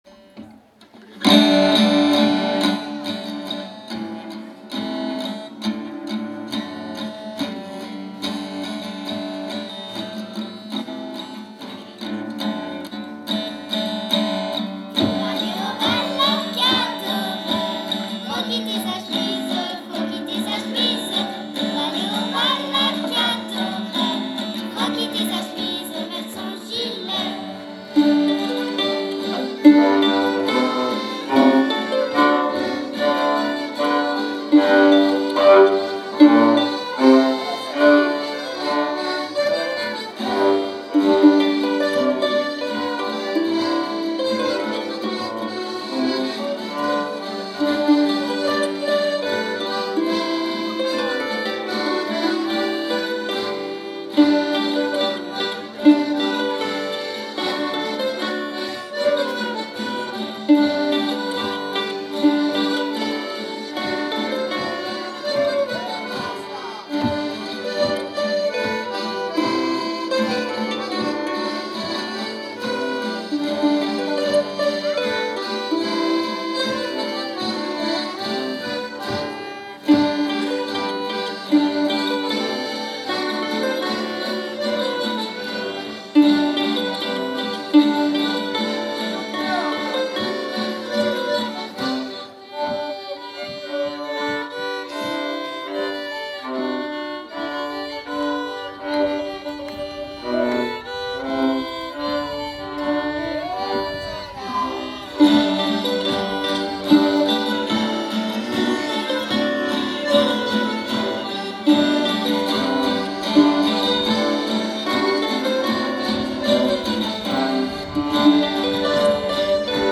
01_debutants.mp3